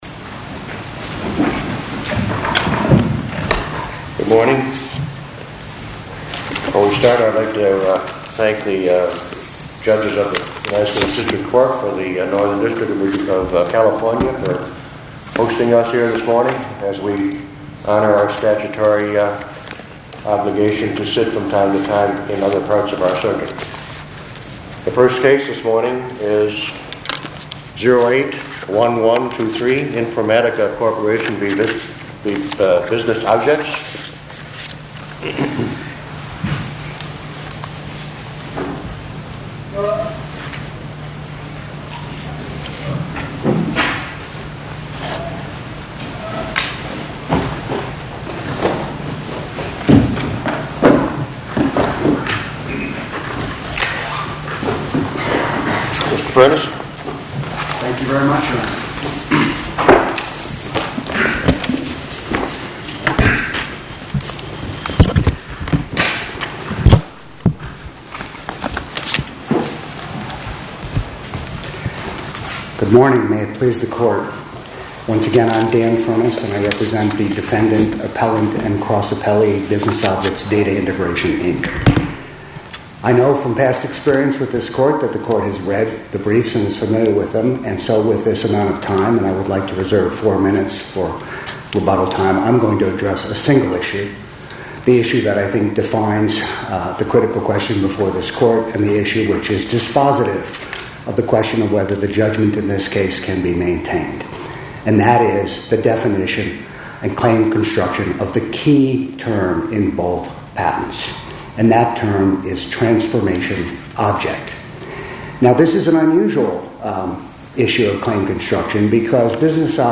Oral argument audio posted: Informatica Corp v Business Objects (mp3) Appeal Number: 2008-1123 To listen to more oral argument recordings, follow this link: Listen To Oral Arguments.